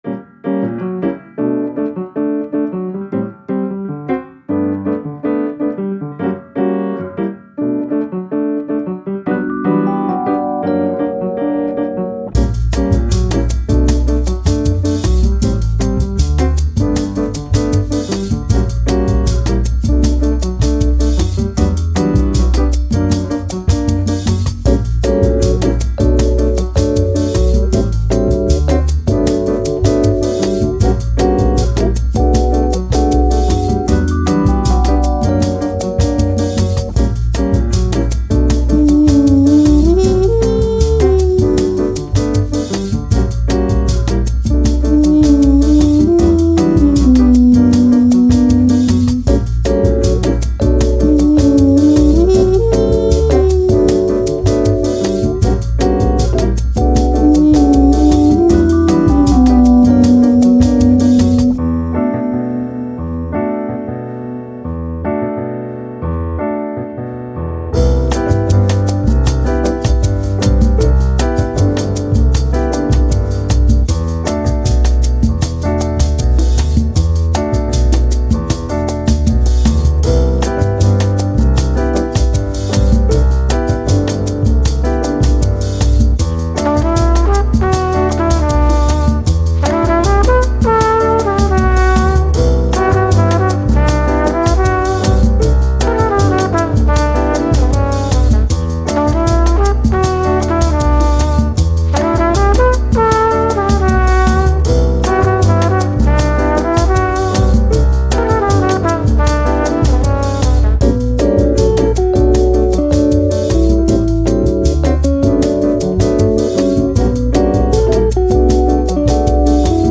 Bossa Nova, Jazz, Latin, Elegant